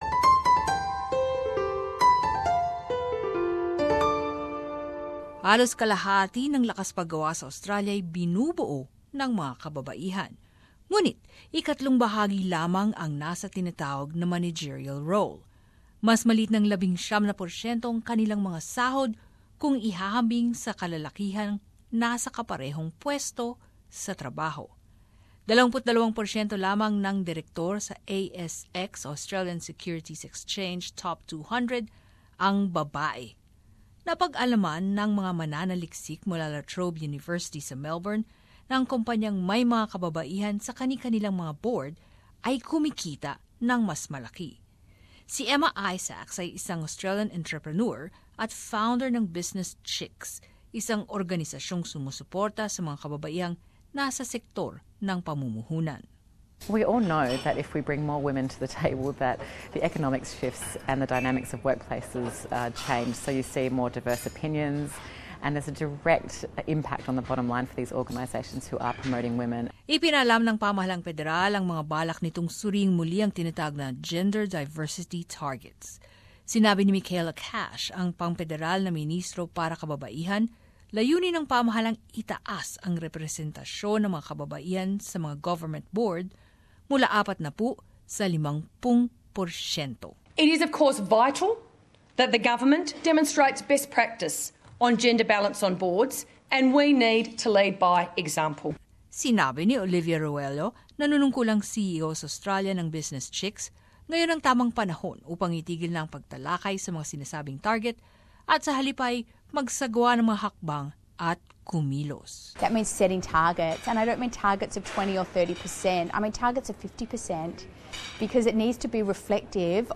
But, as this report shows, a number of Australian businesses appear determined to speed up the process.